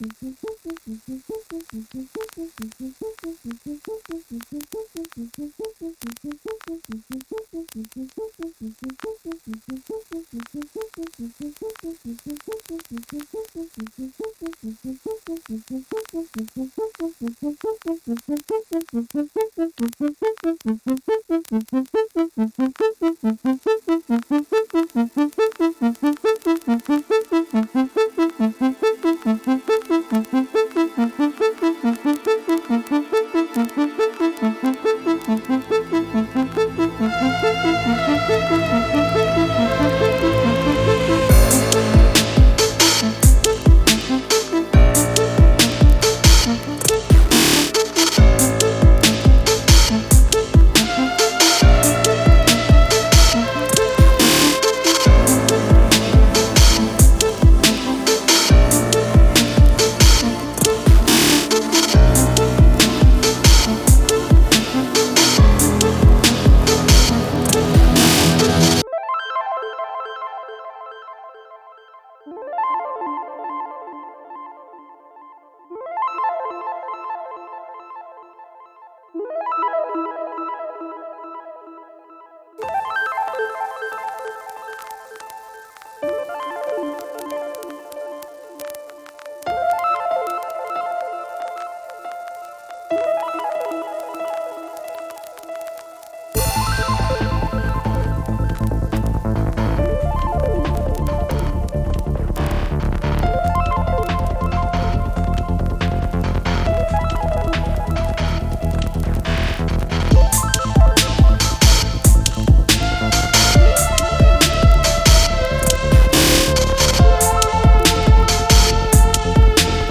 sunrise in some creepy alien world.